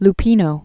(l-pēnō), Ida 1918-1995.